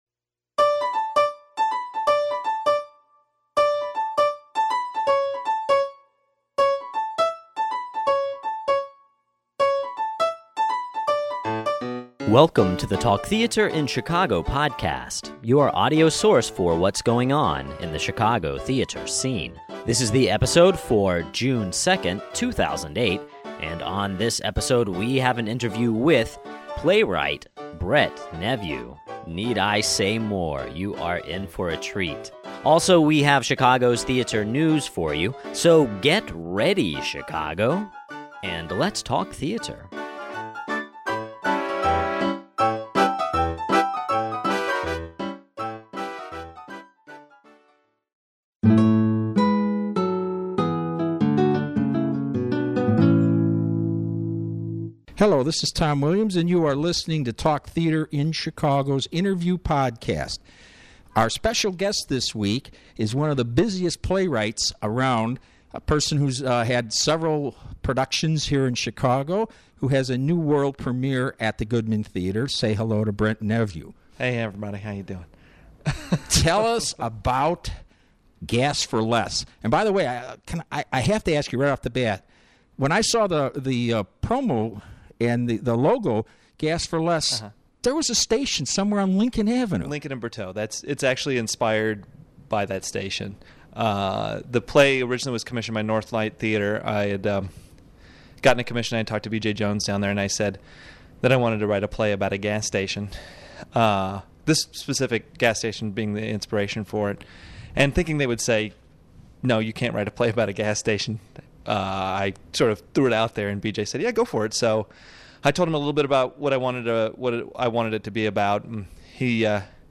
Interview Podcast